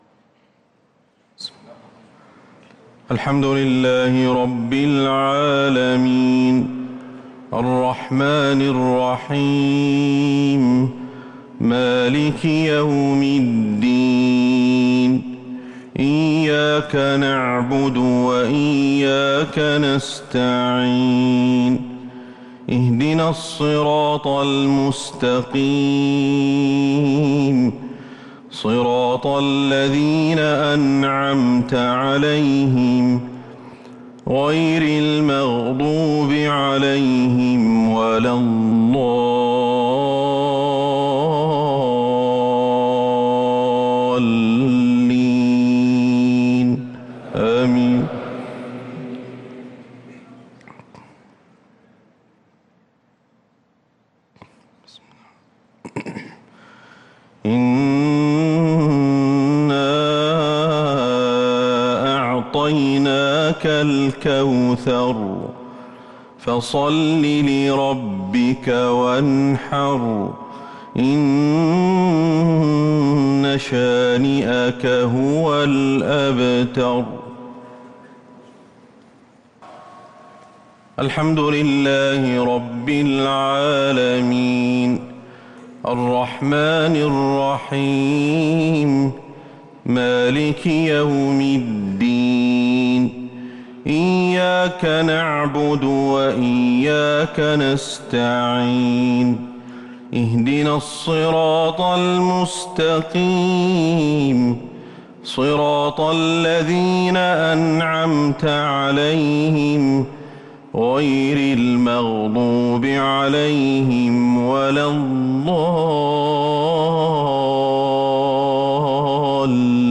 صلاة العشاء للقارئ أحمد الحذيفي 28 رمضان 1443 هـ